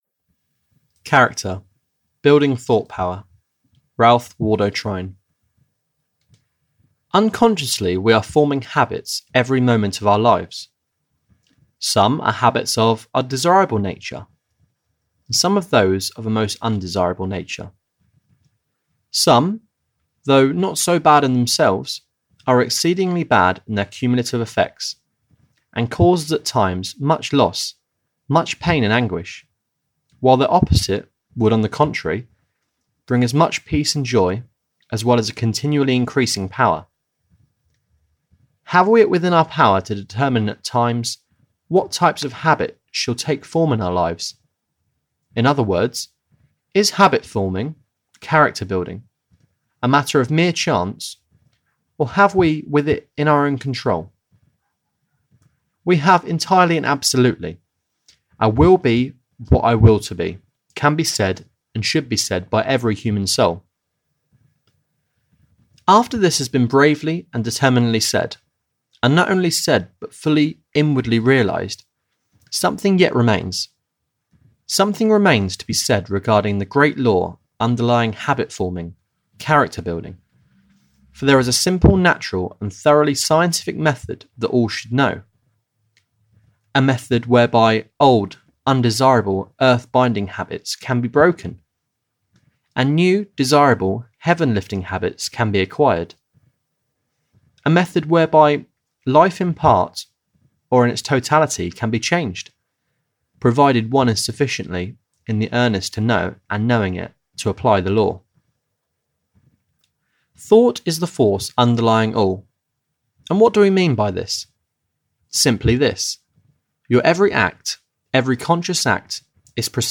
Character - Building Thought Power (EN) audiokniha
Ukázka z knihy